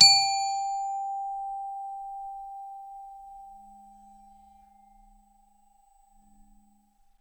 glock_medium_G4.wav